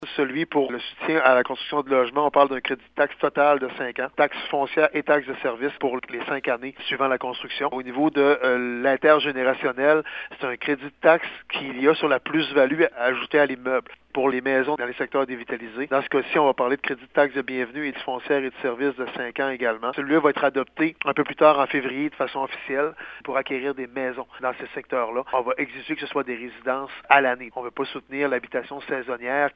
Le maire de Gaspé, Daniel Côté, décrit les avantages pour les promoteurs de la région et d’ailleurs :